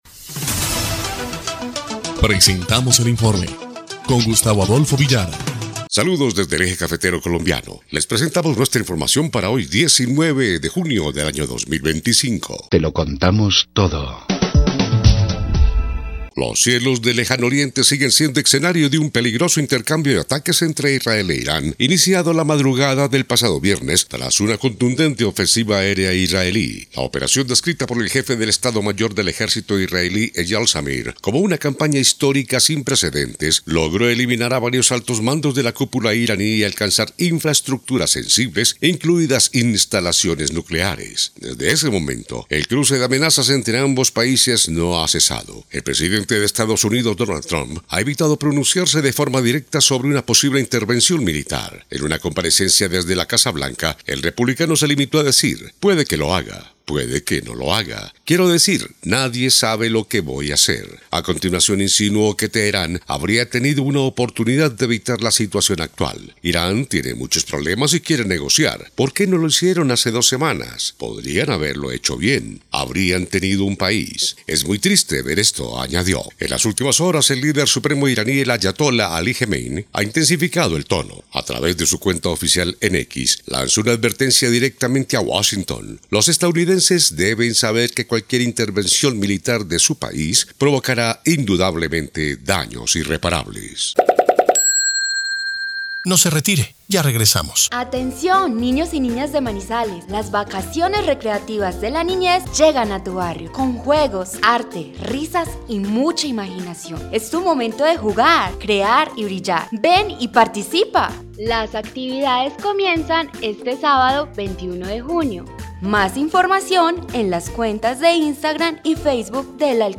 EL INFORME 3° Clip de Noticias del 19 de junio de 2025